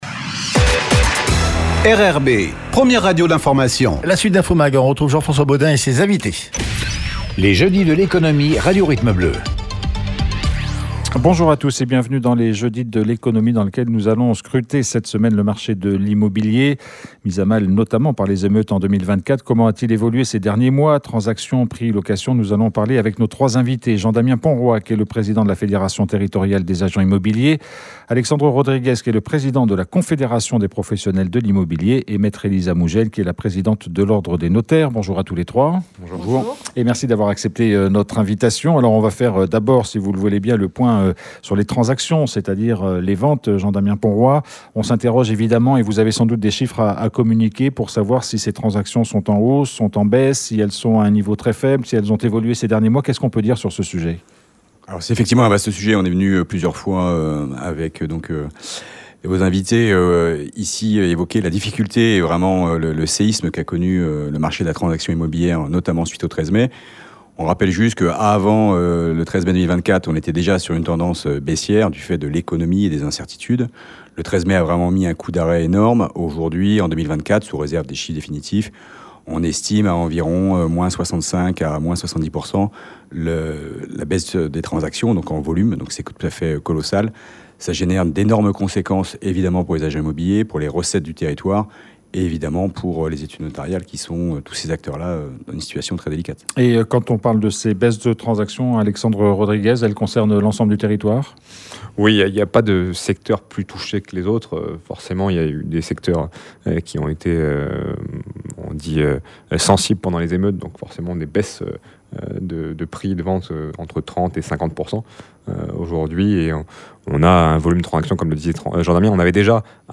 Un rendez-vous d’antenne : ce midi, nous avons scruté le marché de l’immobilier dans les Jeudis de l’Economie Radio Rythme Bleu. Mis à mal par les émeutes de 2024, comment a-t-il évolué ces derniers mois ? Transactions, prix, location, nous en avons parlés avec nos 3 invités